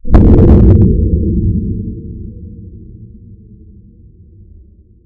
explosion.ogg